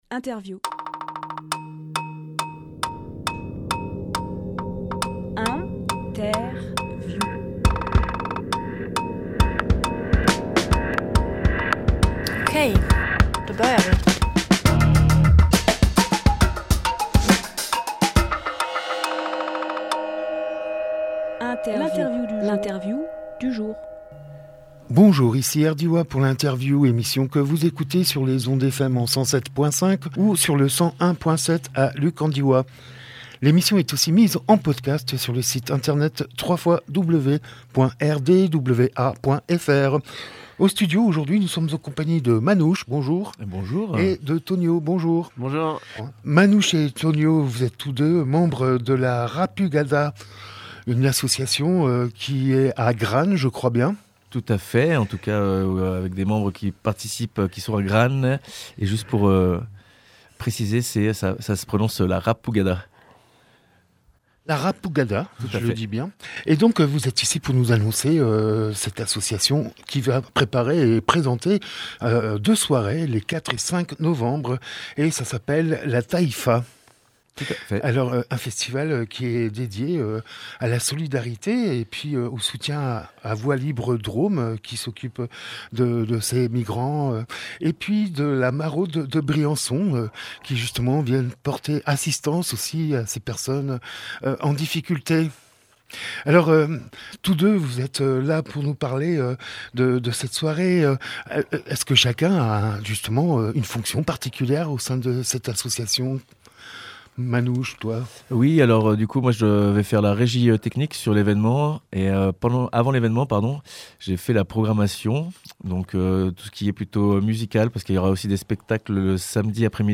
Emission - Interview La Taïfa à Marsanne Publié le 27 octobre 2022 Partager sur…
24.10.22 Lieu : Studio RDWA Durée